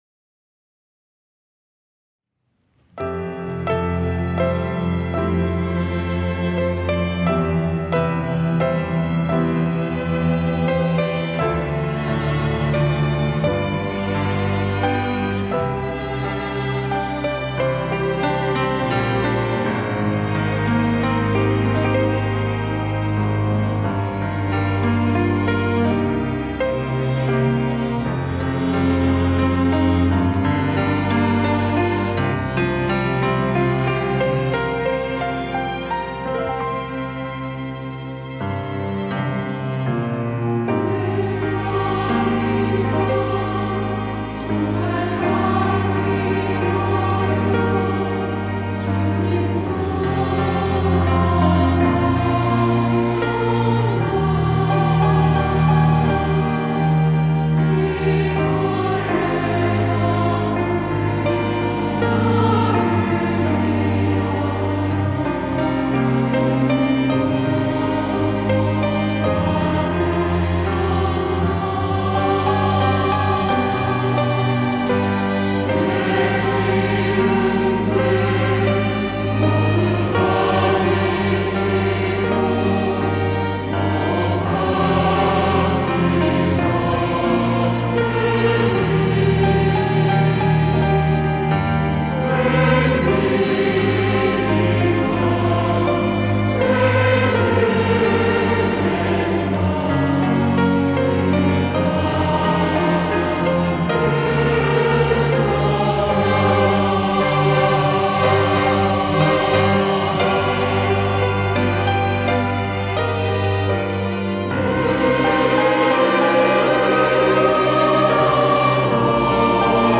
호산나(주일3부) - 웬말인가 날 위하여
찬양대